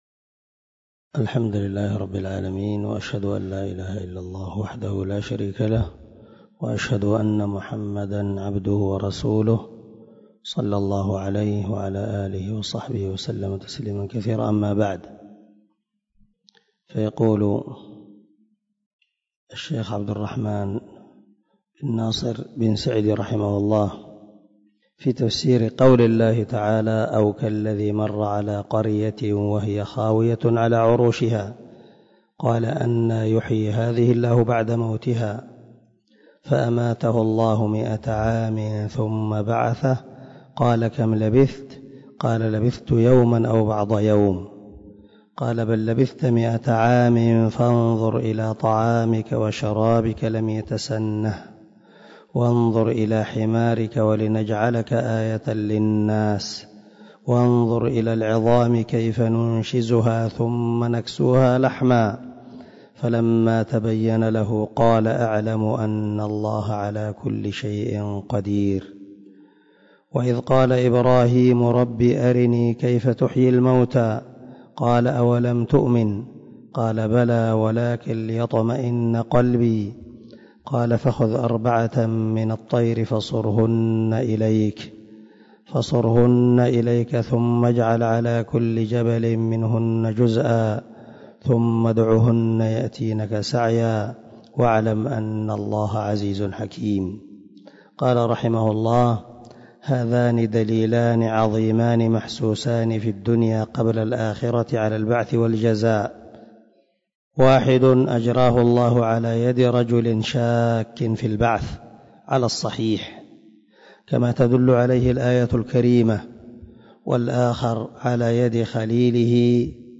139الدرس 129 تابع تفسير آية ( 259 – 260 ) من سورة البقرة من تفسير القران الكريم مع قراءة لتفسير السعدي
دار الحديث- المَحاوِلة- الصبيحة.